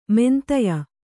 ♪ mentaya